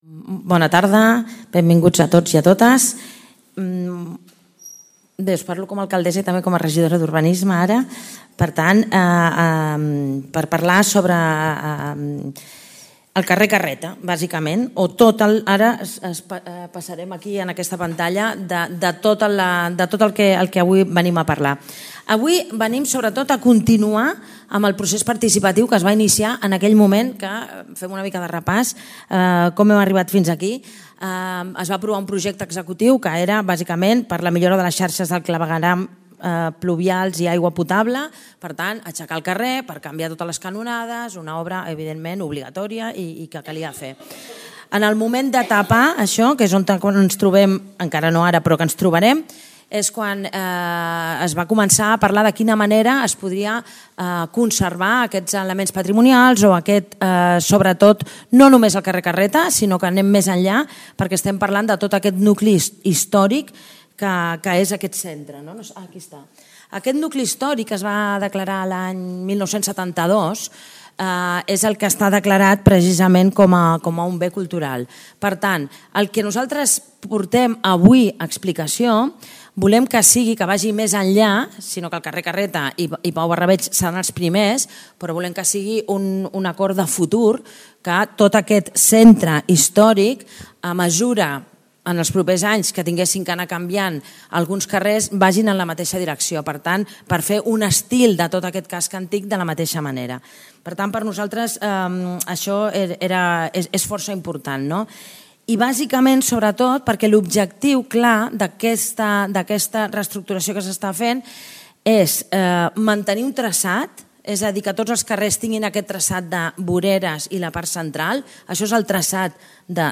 El Miramar ha acollit una sessió informativa on s'han presentat tres propostes de pavimentació per al carrer Carreta i que s'estendran al nucli històric en cas de futures actuacions.